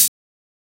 HI HAT RAW.wav